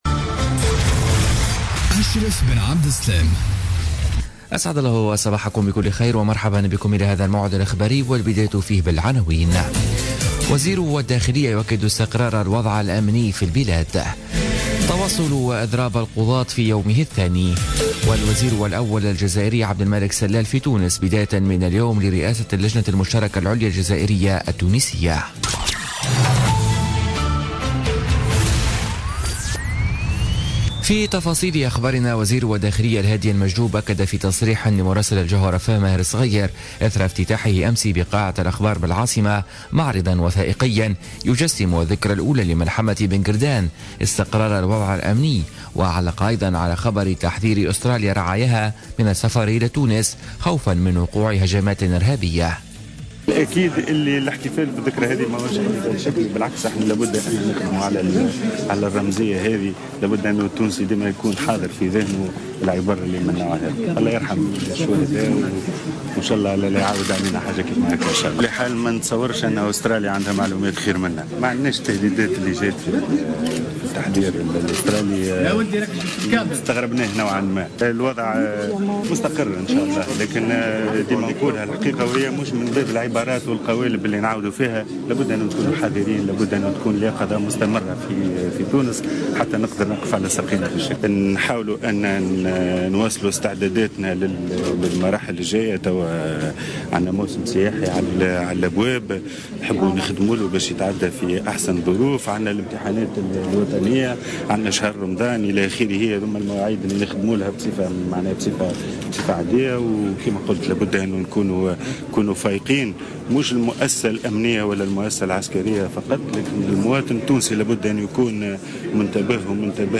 Journal Info 07h00 du jeudi 9 mars 2017